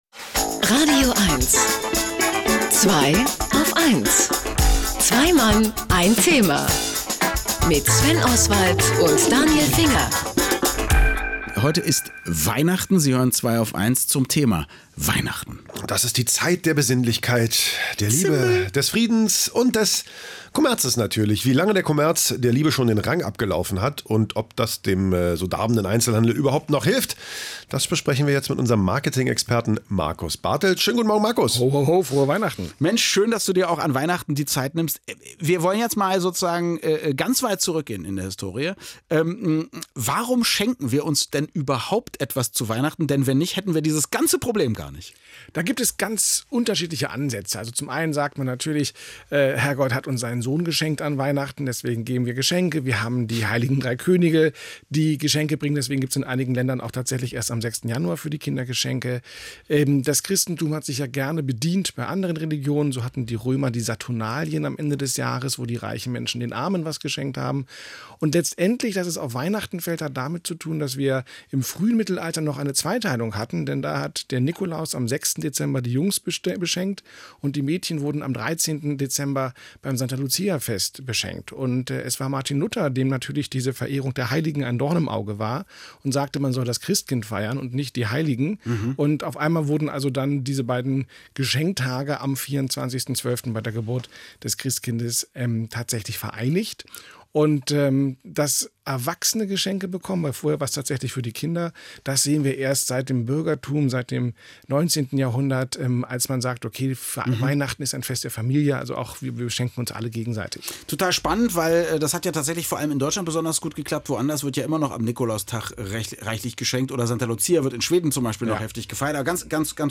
Auch am heiligsten aller heiligen Sonntage führt mich mein Weg ins romantische Potsdam-Babelsberg zum „radioeins„-Studio, um dort mit den Jungs von „Zweiaufeins“ ein wenig über Geschenke, Marketing und Weihnachten zu plauschen: